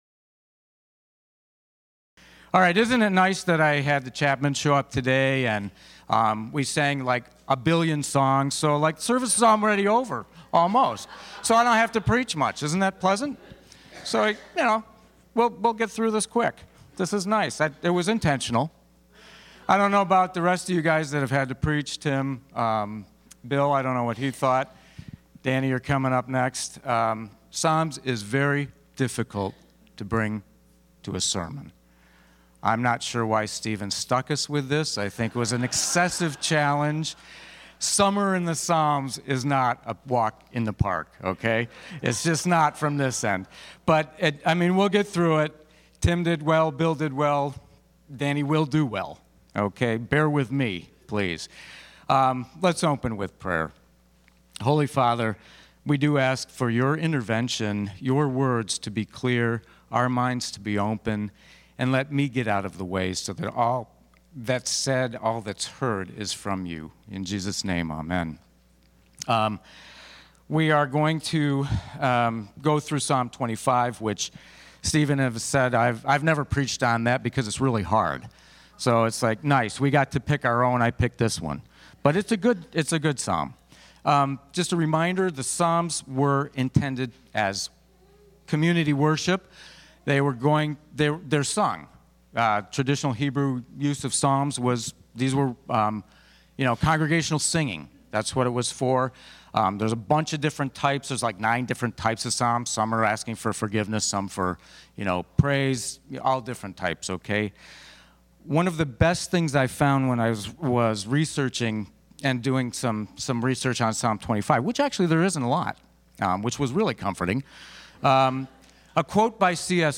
Summer in the Psalms Passage: Psalm 25 Service Type: Sunday Morning Service « What Are You Waiting For?